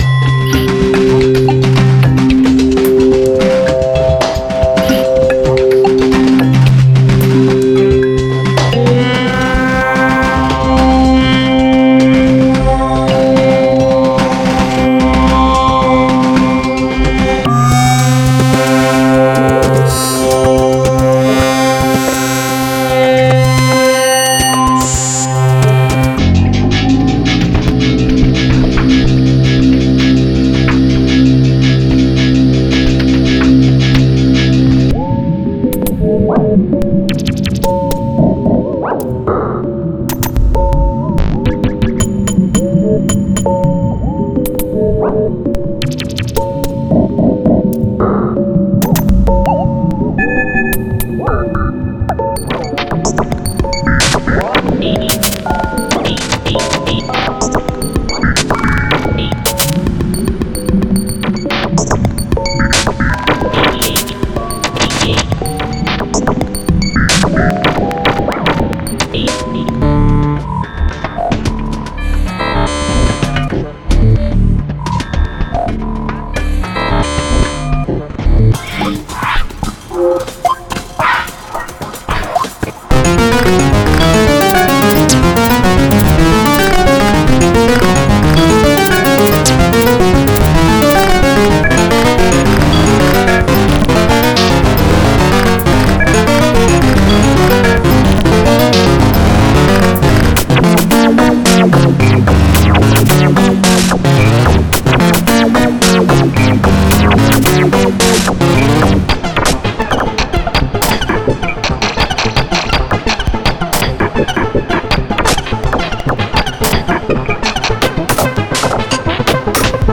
Wav Loops